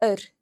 When R is not the first letter in a word and occurs next to a, o, or u, it is considered broad, and can be heard in fear (a man):